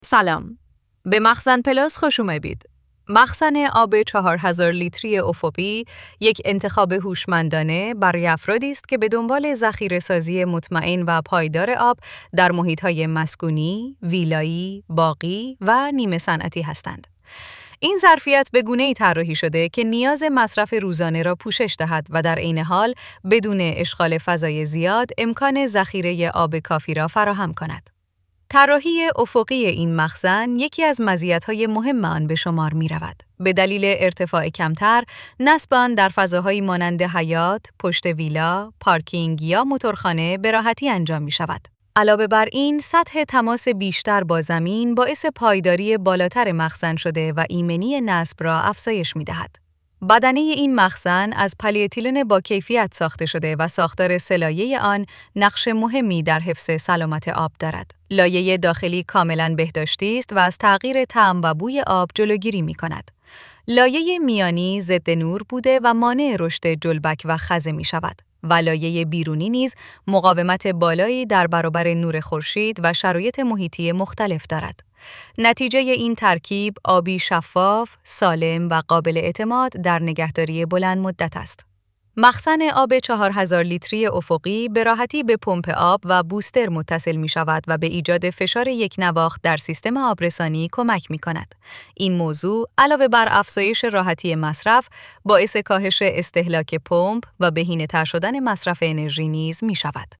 متن-به-صدا.mp3